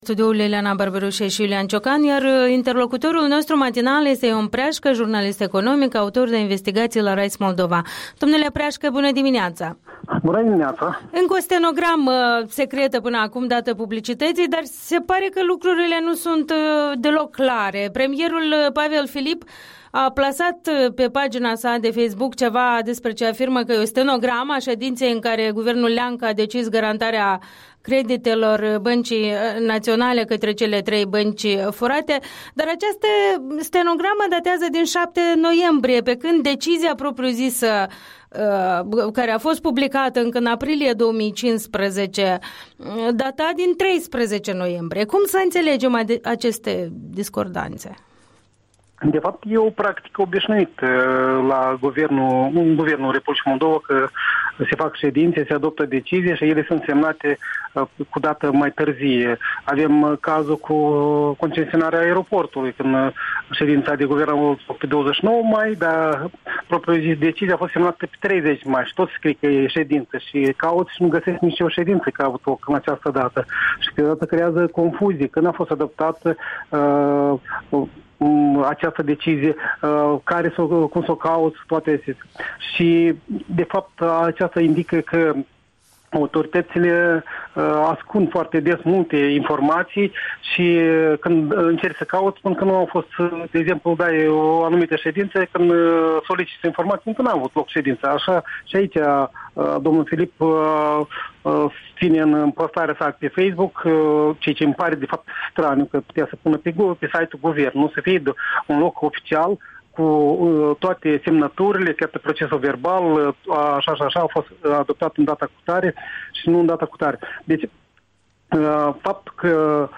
Interviul dimineții cu jurnalistul de investigație specializat în chestiuni economice.